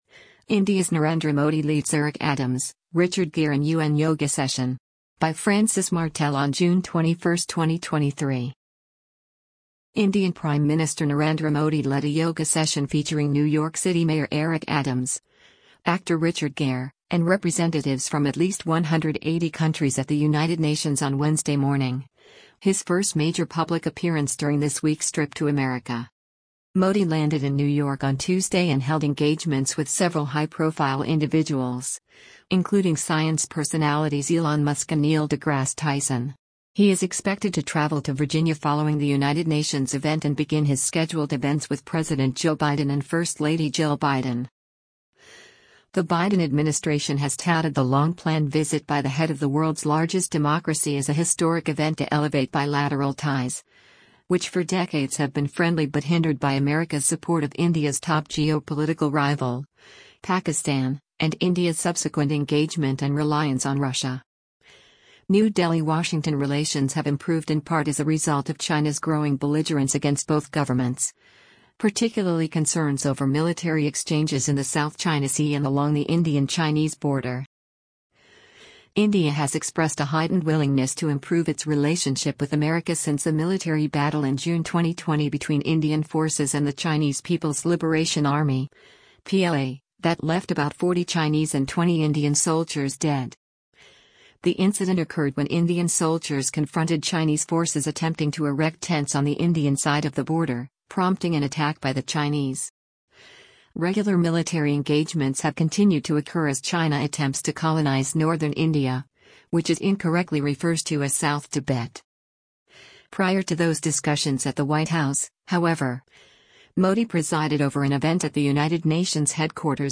Prior to those discussions at the White House, however, Modi presided over an event at the United Nations headquarters in New York to celebrate the International Day of Yoga.
Modi addressed a large crowd on the United Nations grounds, all equipped with yellow yoga mats and prepared to participate in a session following remarks by the prime minister and other special guests.